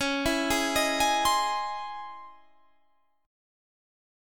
C#mM9 Chord
Listen to C#mM9 strummed